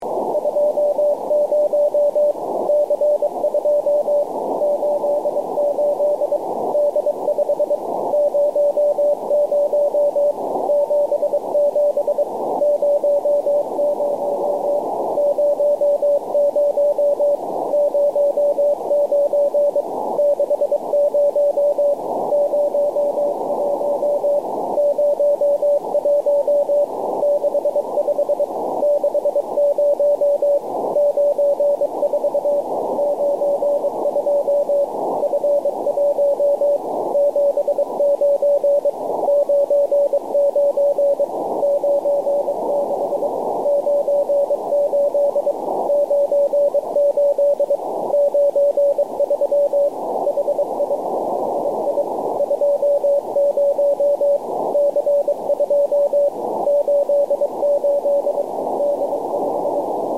Записи сигналов спутников
Модуляция: CW